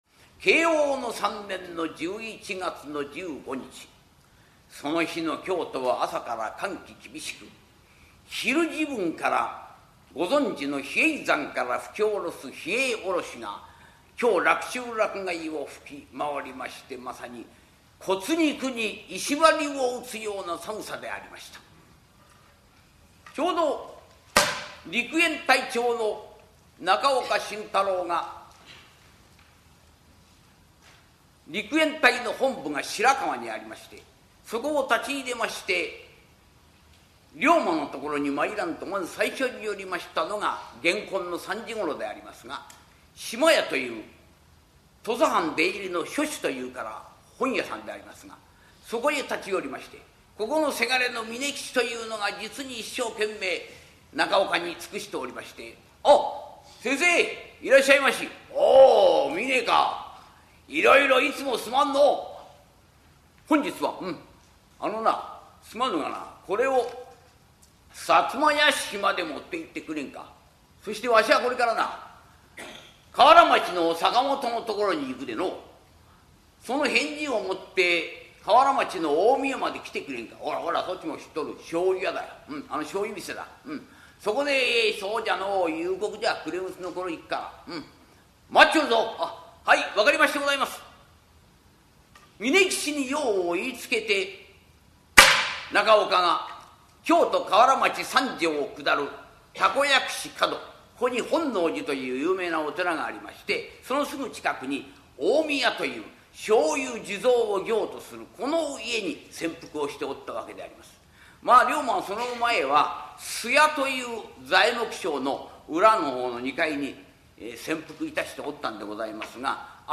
[オーディオブック] 講談 龍馬討たる
ハリセンで釈台を叩き「パパン」という音を響かせて調子良く語る、江戸時代から伝わる日本伝統の話芸「講談」。講談協会に所属する真打を中心とした生粋の講談師たちによる、由緒正しき寄席で行われた高座を録音した実況音源！